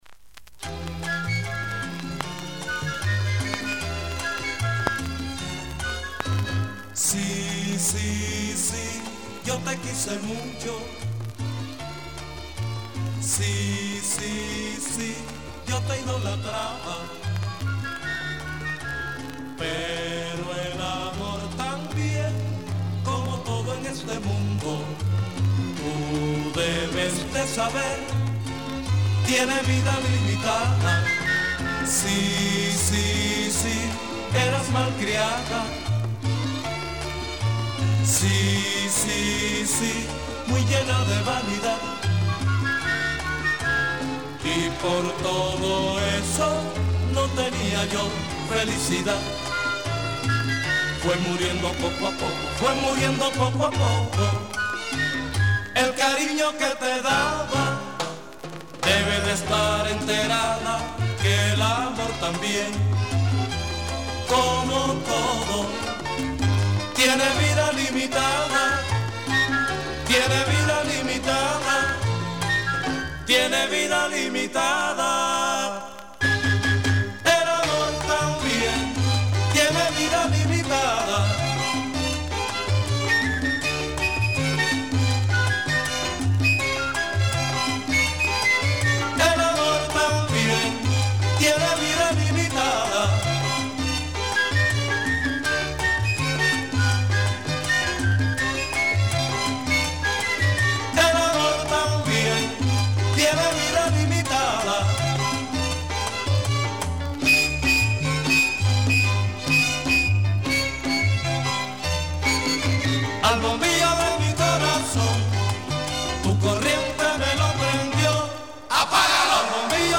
Charanga
Son, guaracha, cha cha chaを演奏するオルケスタ
本作品は７０年代の物と思われる４曲入りシングル盤